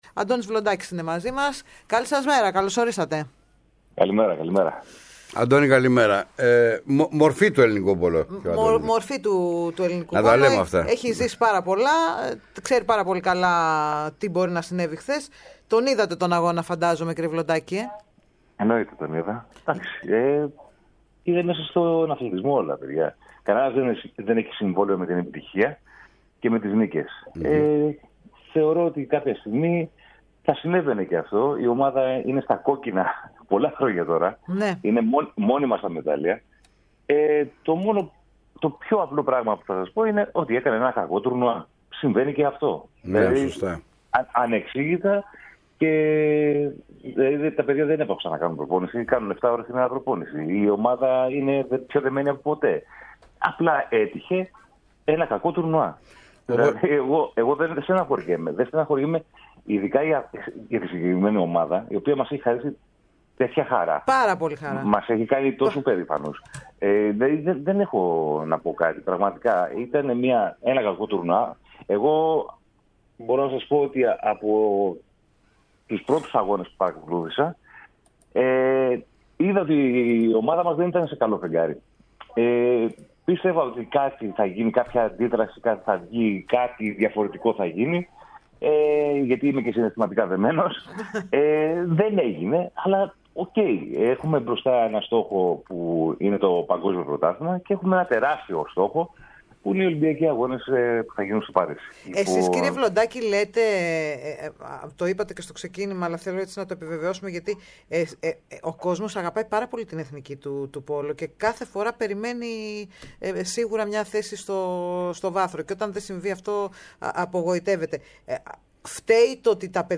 Στην ΕΡΑ ΣΠΟΡ και την εκπομπή “Οι Τρισδιάστατοι” μίλησε ο Παγκόσμιος Πρωταθλητής και Ολυμπιονίκης στο πόλο Αντώνης Βλοντάκης. Αναφέρθηκε στην Εθνική Ανδρών και γυναικών, αλλά στην ανάγκη για στήριξη από τον κόσμο και την πολιτεία στο άθλημα που έχει να προσφέρει πολλά ακόμη.